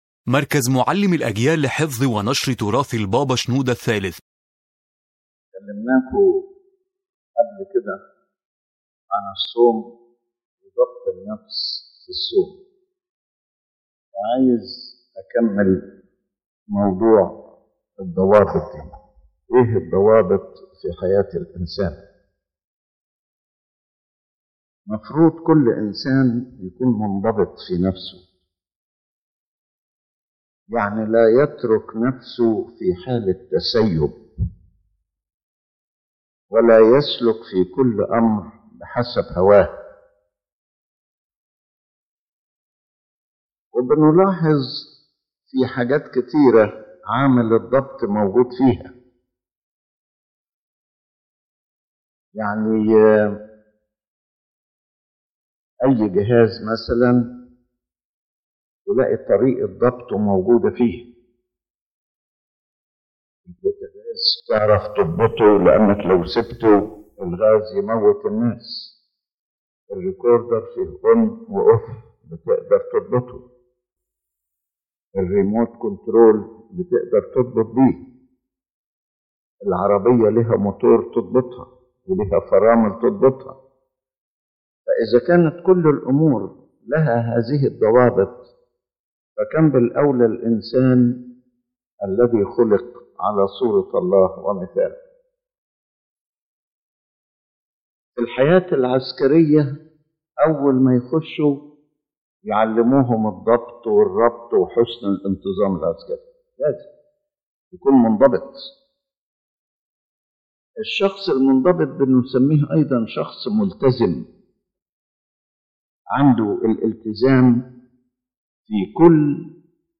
The lecture revolves around the importance of self-control in the life of a Christian, as it is a fundamental element of spiritual growth, protecting a person from following personal desires and sin, and achieving true freedom, which is liberation from the authority of sin, not freedom to commit it.